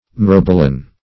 Myrobalan \My*rob"a*lan\, Myrobolan \My*rob"o*lan\, n. [L.